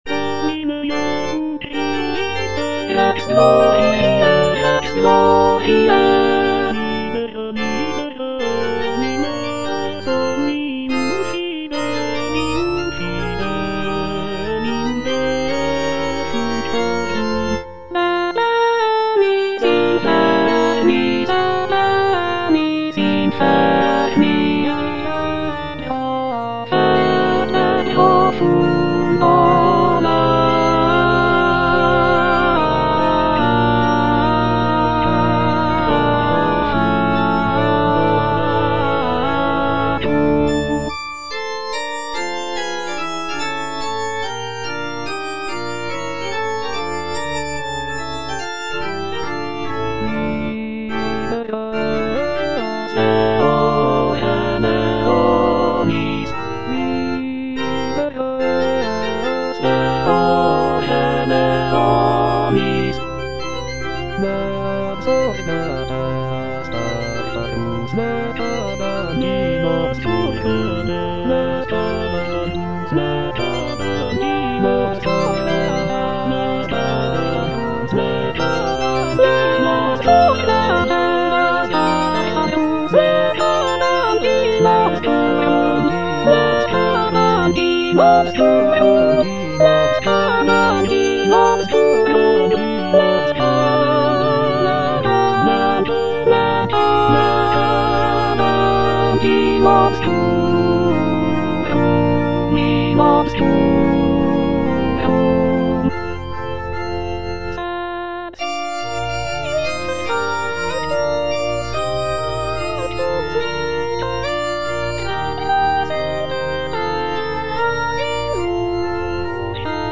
Soprano (Emphasised voice and other voices) Ads stop